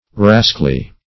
Rascally \Ras"cal*ly\ (r[a^]s"kal*l[y^]), a.